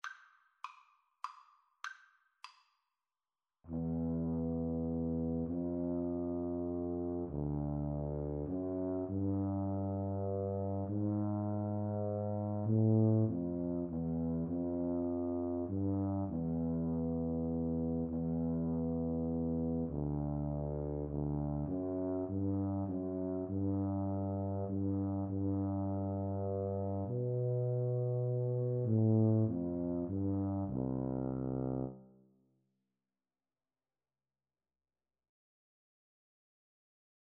C major (Sounding Pitch) (View more C major Music for Tuba Duet )
3/4 (View more 3/4 Music)
Moderato
Tuba Duet  (View more Easy Tuba Duet Music)